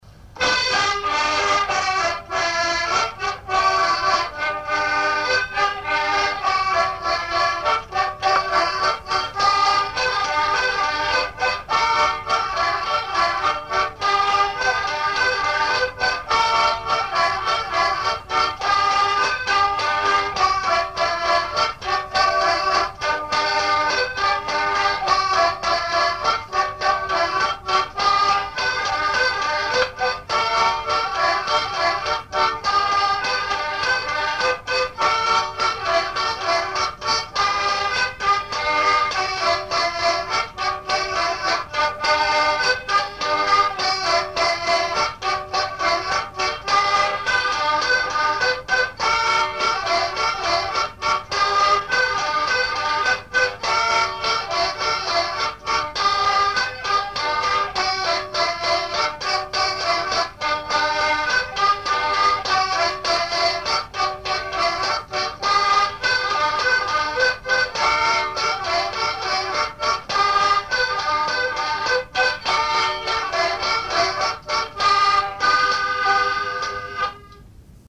danse : polka lapin
enregistrements du Répertoire du violoneux
Pièce musicale inédite